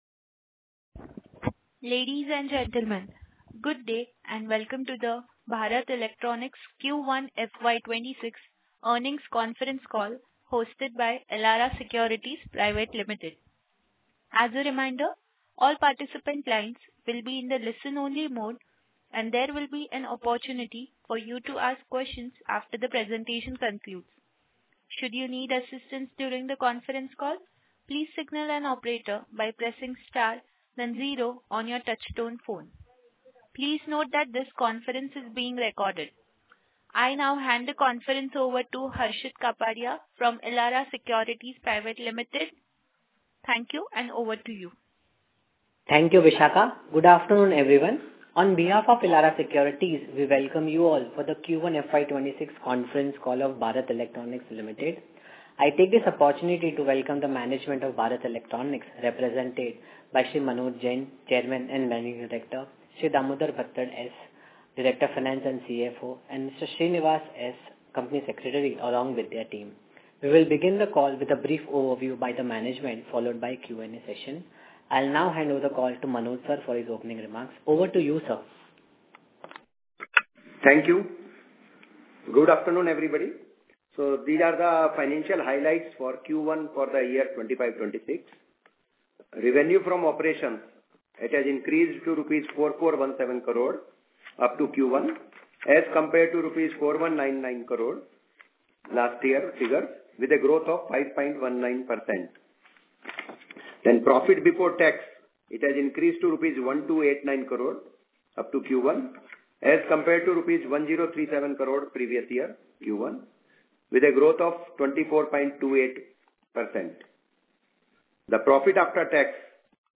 कॉन्फ्रेंस कॉल की ऑडियो रिकॉर्डिंग – BEL
वित्त वर्ष 2025-26 की तीसरा तिमाही की कॉनकॉल ऑडियो रिकॉर्डिंग